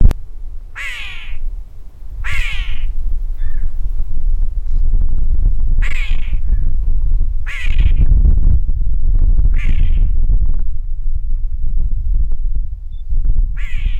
Solitary Cacique (Cacicus solitarius)
Life Stage: Adult
Location or protected area: Bañados cerca del rio san francisco
Condition: Wild
Certainty: Photographed, Recorded vocal